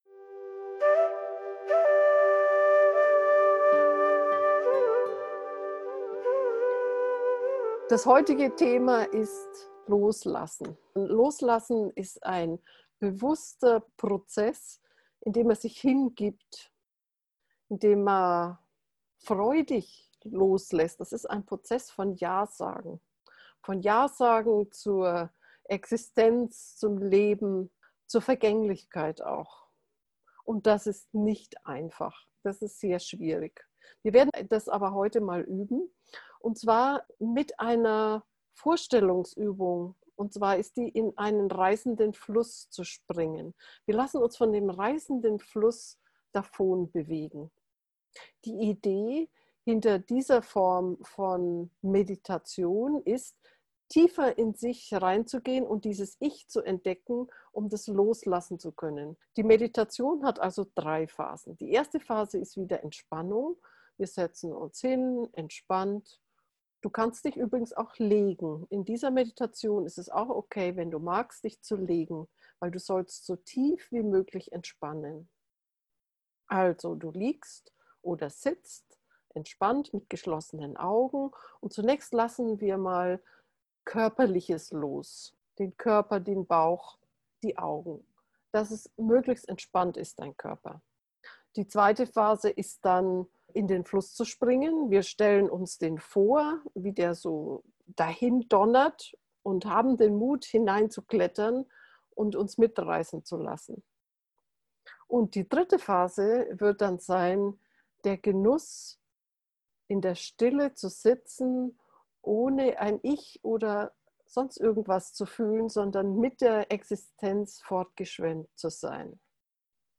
Deshalb diese Meditationübung: Du wirst ins Loslassen geführt. Du stellst dir einen reißenden Fluss vor, springst hinein und lässt dich von den schnellen Wellen davontragen.
loslassen-einschlafen-gefuehrte-meditation